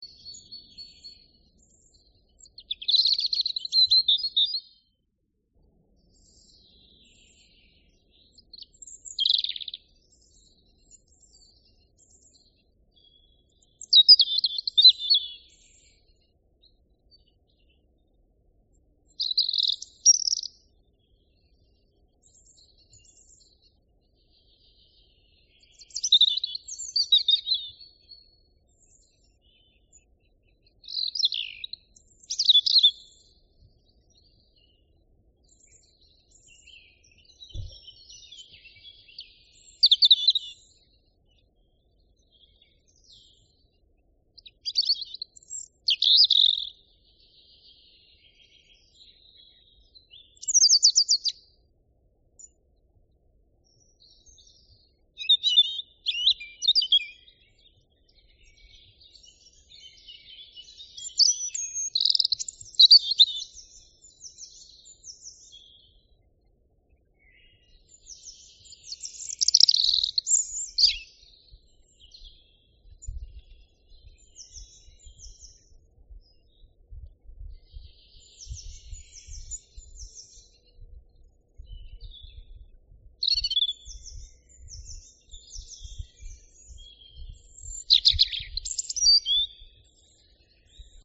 Birds-singing-small.mp3